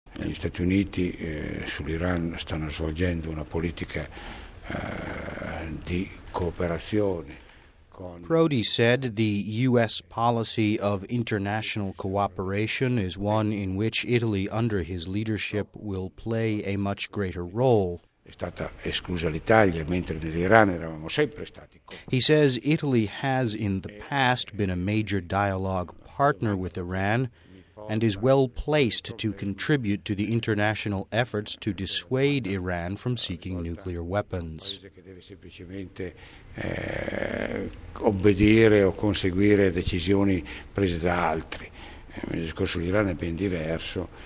(3 May 06 - RV) The new prime minister of Italy, Romano Prodi spoke to Vatican Radio. Here is an excerpt: For more on this exclusive interview, please listen to our French and Italian programmes.